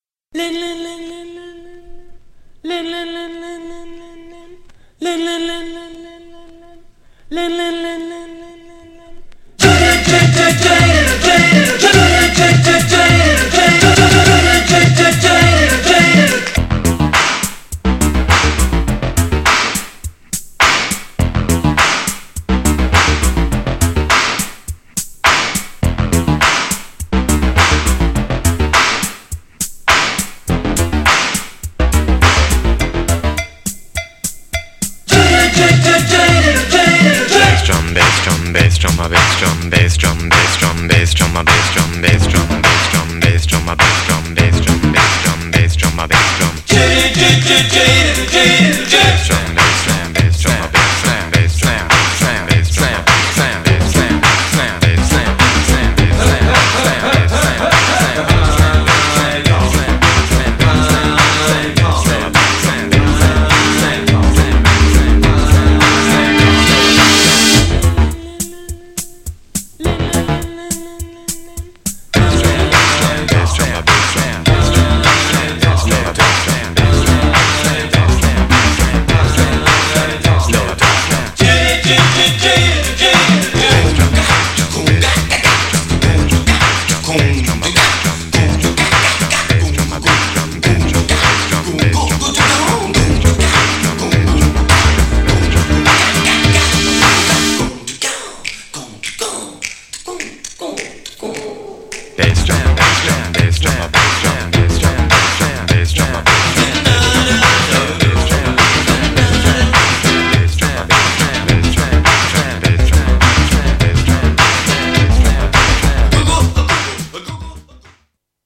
80'sエレクトロDISCO!!
GENRE Dance Classic
BPM 121〜125BPM